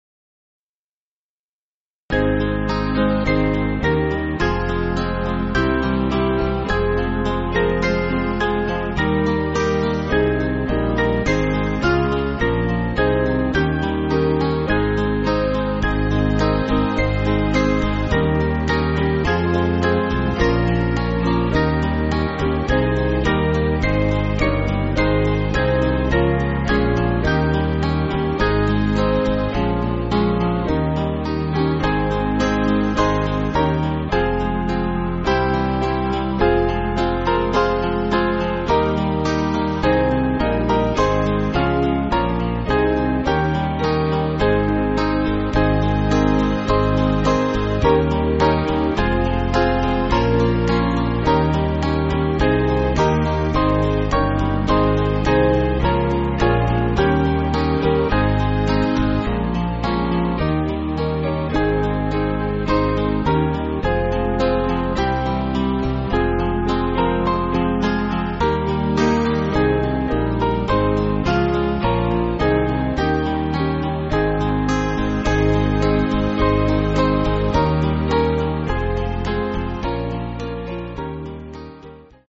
Small Band
(CM)   7/Ab